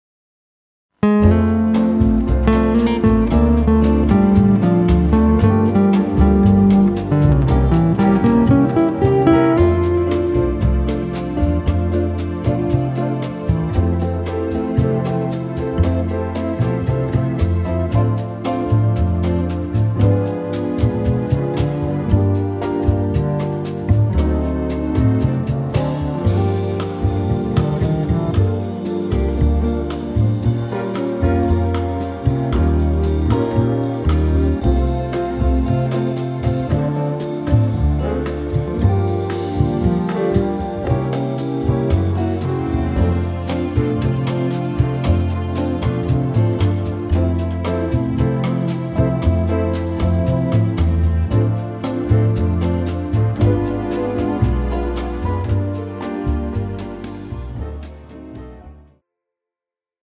bossanova style
tempo 115 bpm
male backing track
This track is in medium tempo bossanova style.